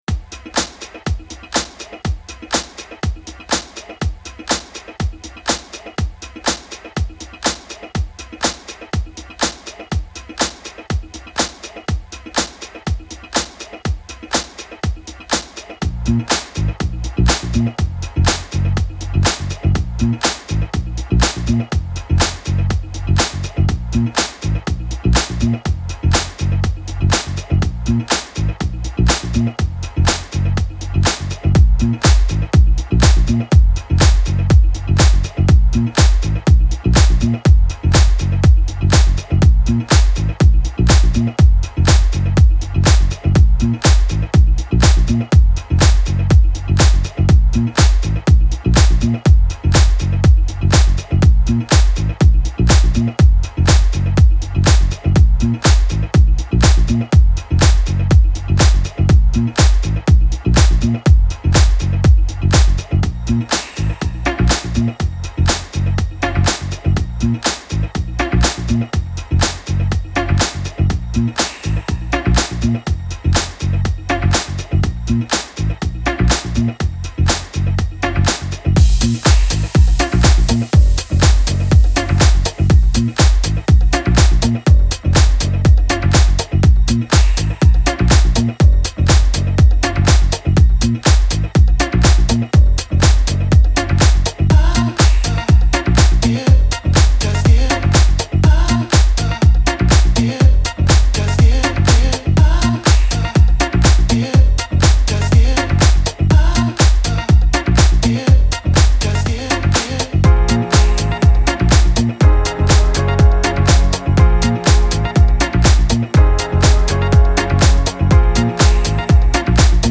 Genre: Chillout, Deep House.